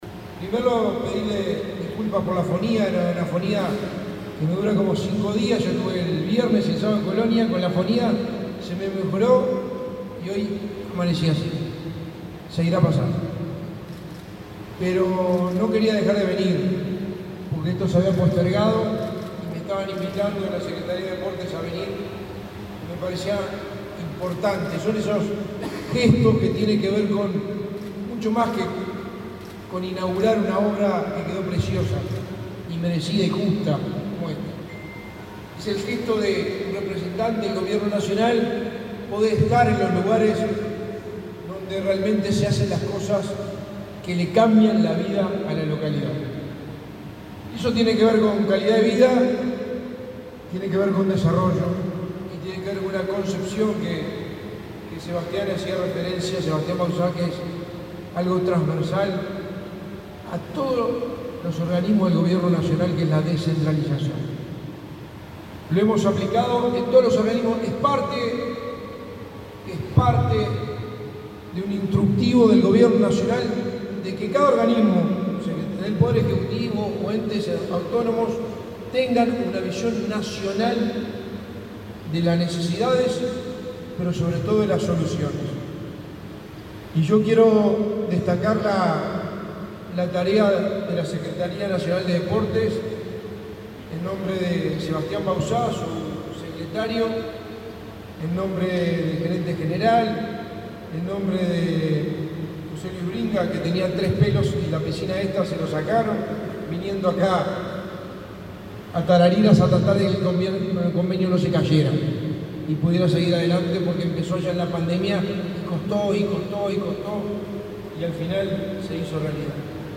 Palabras del secretario de Presidencia, Álvaro Delgado
El secretario de Presidencia, Álvaro Delgado, participó, este miércoles 6 en Colonia, de la inauguración de las obras de techado y cerramiento de la